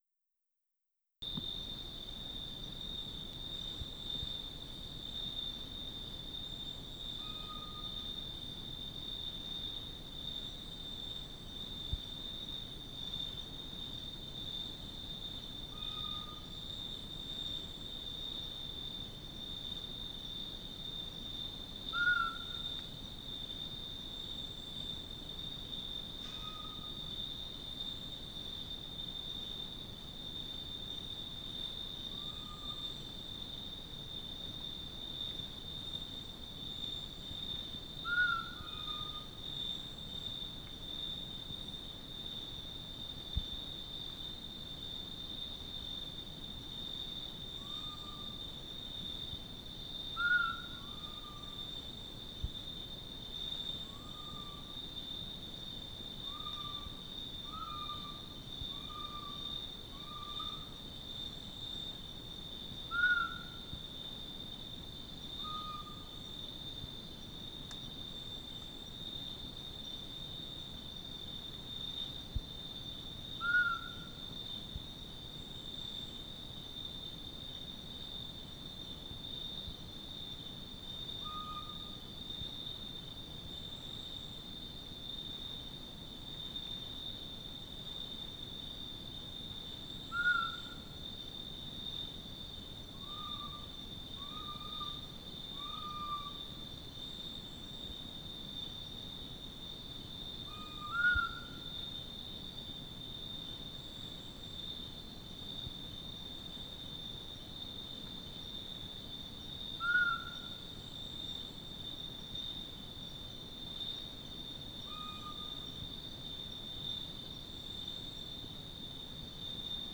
Edwards' camp, 600m. - night, owls (I guess), + very high-pitch crickets | BioAcoustica
Non-specimen recording: Soundscape